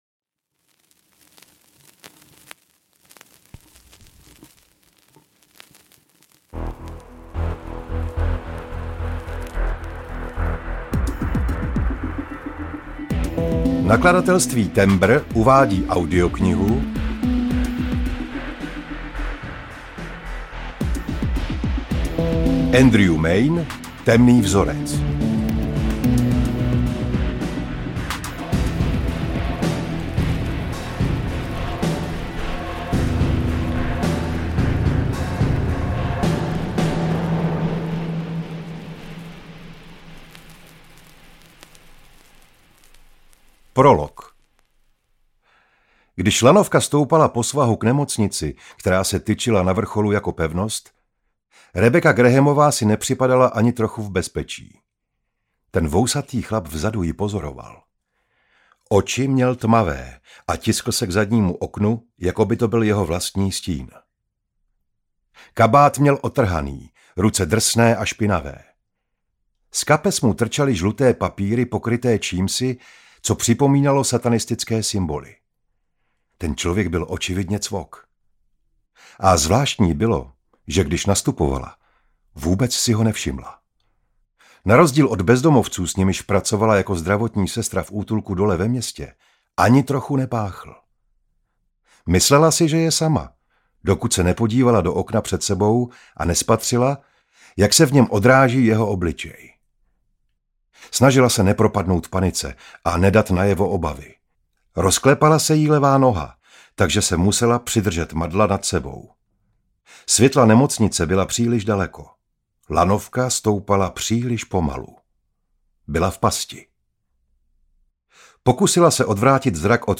Temný vzorec audiokniha
Ukázka z knihy